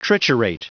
Prononciation du mot triturate en anglais (fichier audio)
Prononciation du mot : triturate